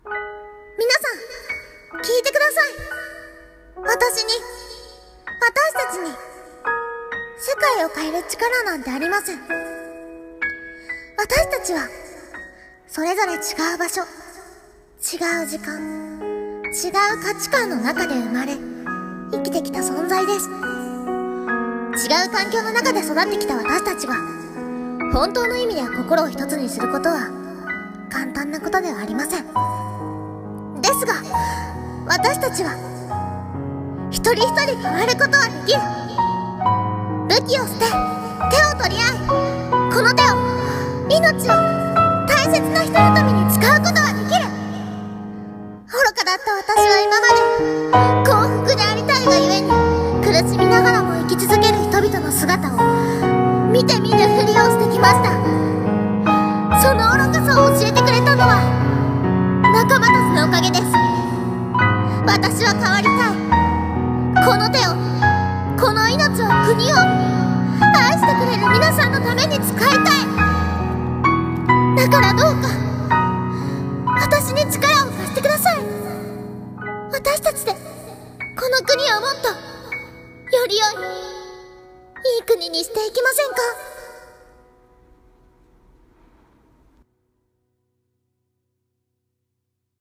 【一人声劇】新国王の演説